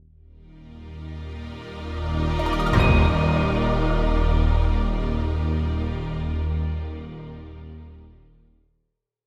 Category 🎮 Gaming